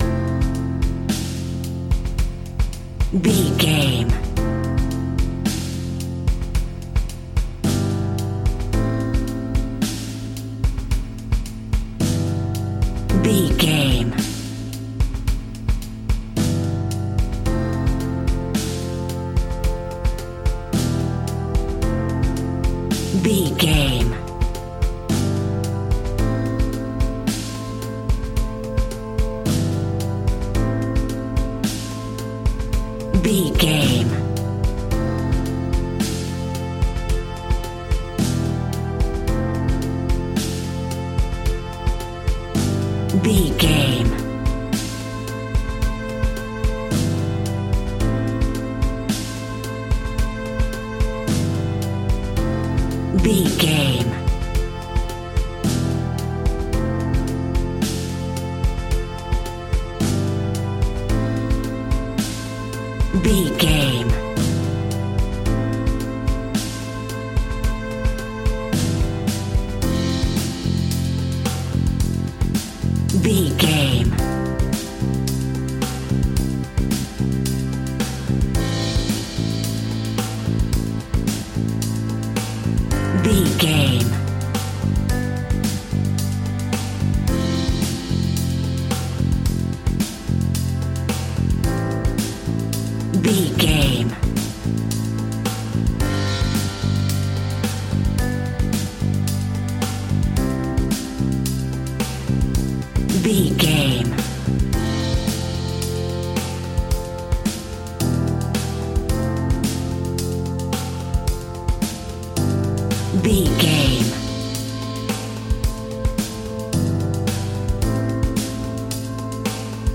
Warm Indie Pop Rock.
Ionian/Major
indie rock
sunshine pop music
drums
bass guitar
electric guitar
piano
hammond organ